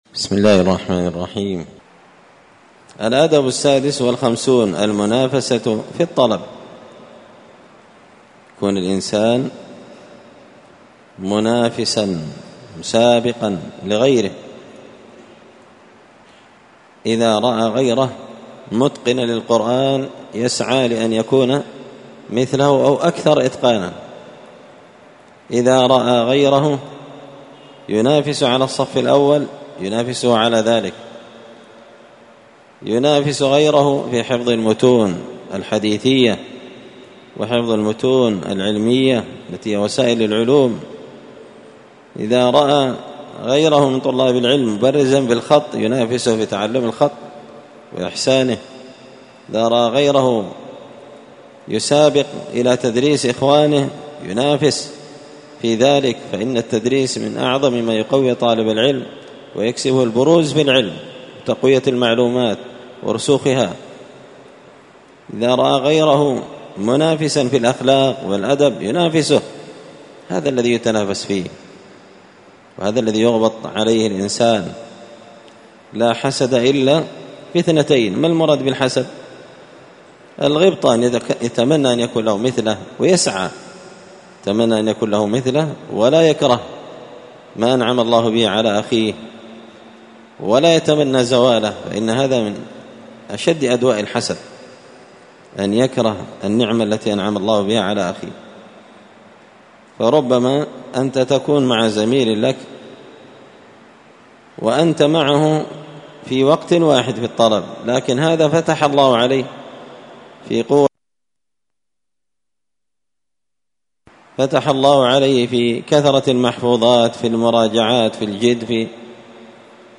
الأثنين 8 ذو الحجة 1444 هــــ | الدروس، النبذ في آداب طالب العلم، دروس الآداب | شارك بتعليقك | 7 المشاهدات
مسجد الفرقان قشن_المهرة_اليمن